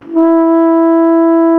TENORHRN E 2.wav